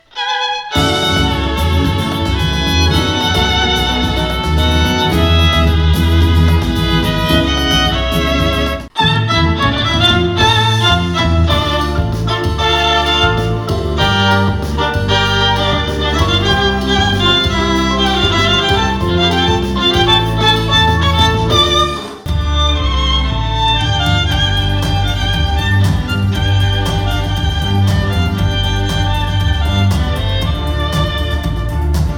Podczas gali wystąpił duet skrzypcowy Queens of violin, który zagrał dobrze znane nie tylko utwory muzyki klasycznej, ale również przeboje muzyki rozrywkowej.
2014-duet-skrzypcowy-fragment.mp3